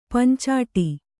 ♪ pancāṭi